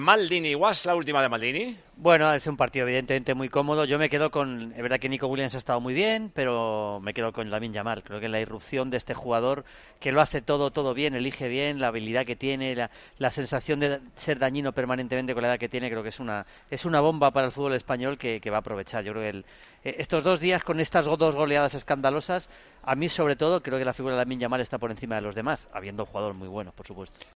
AUDIO: El especialista de COPE, Julio Maldonado, analizó el momento de la selección y dejó claro qué jugador destaca por encima de todos.